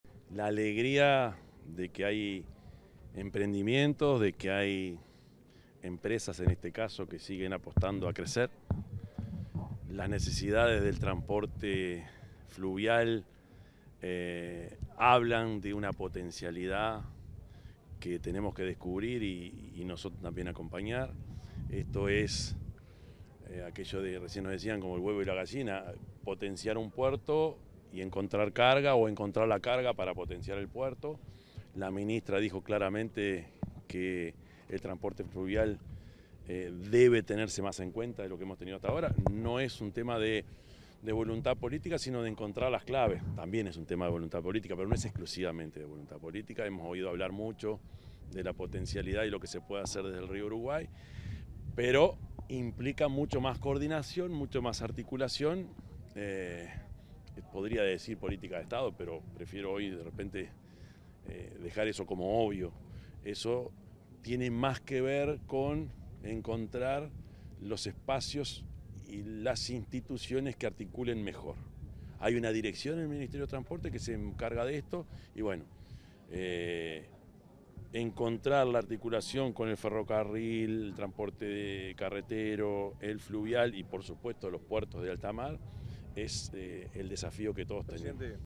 El presidente de la República, profesor Yamandú Orsi, dialogó con los medios de prensa tras la inauguración del remolcador TFF Anglo en Fray Bentos,